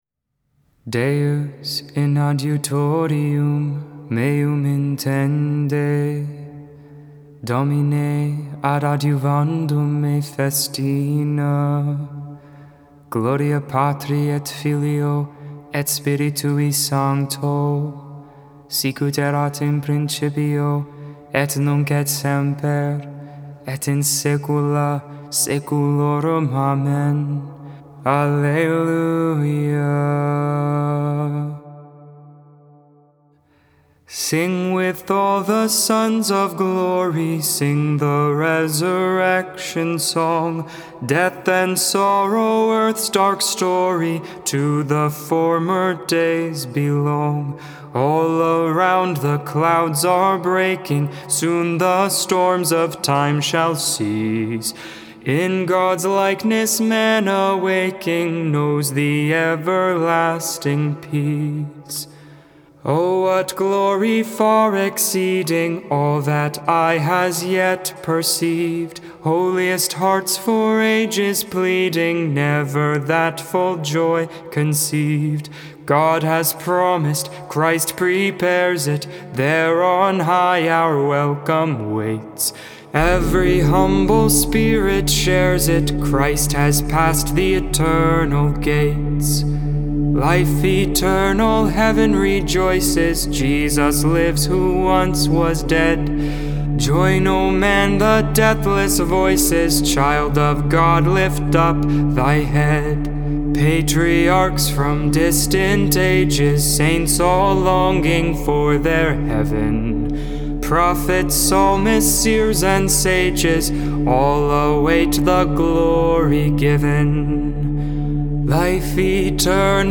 Lauds, Morning Prayer for the 4th Sunday in Eastertide, May 8th, 2022.